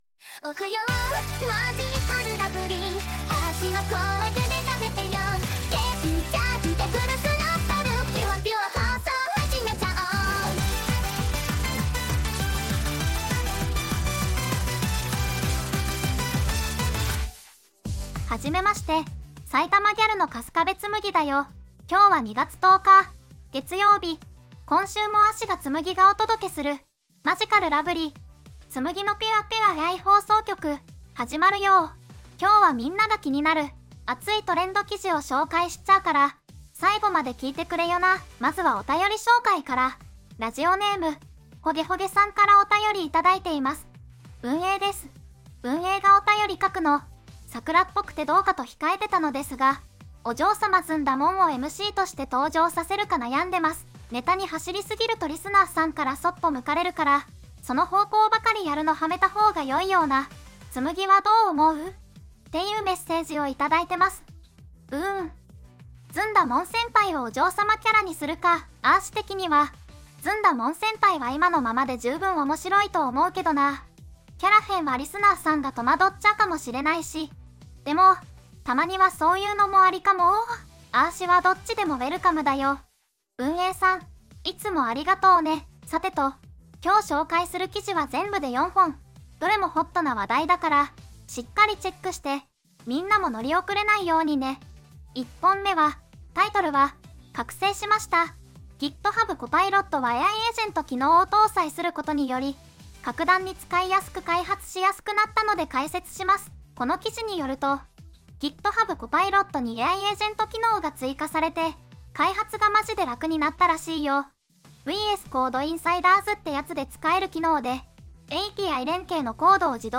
VOICEVOX:春日部つむぎ